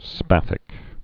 (spăthĭk)